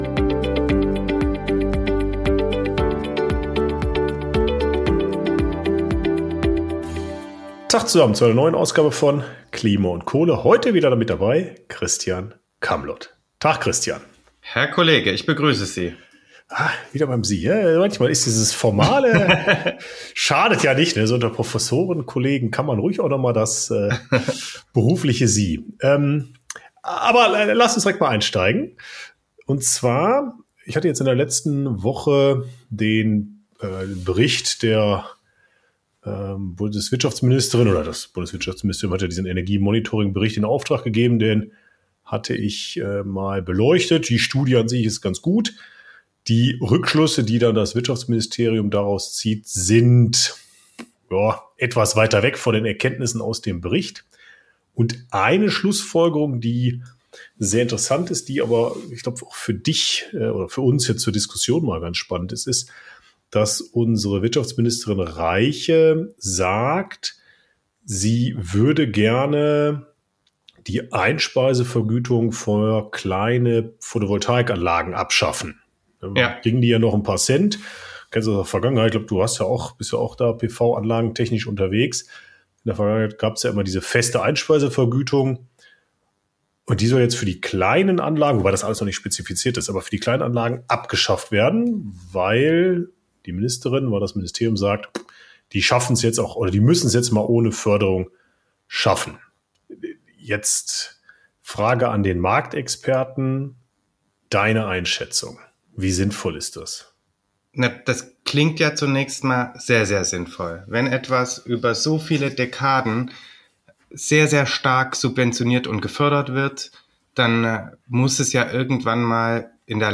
#180 Keine Vergütung mehr für PV-Dachanlagen? Gespräch